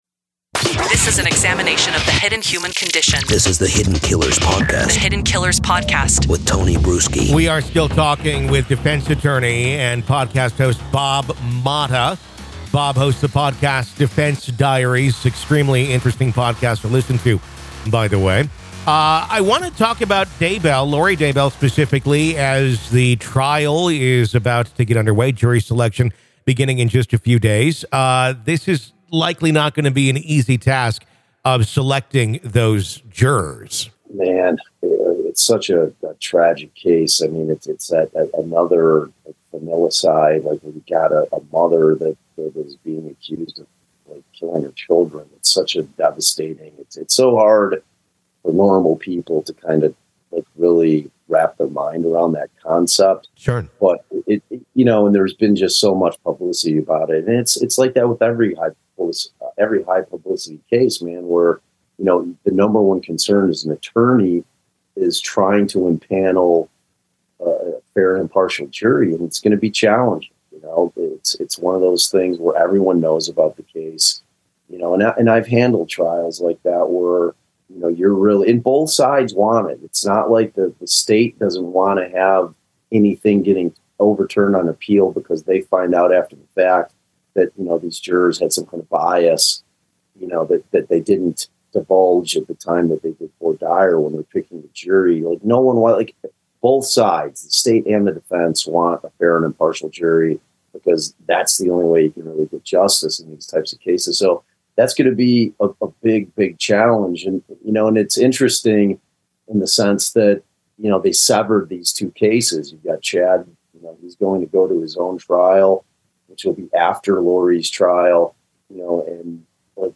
The interview sheds light on the case and gives viewers an understanding of the potential strategies and outcomes of the trial.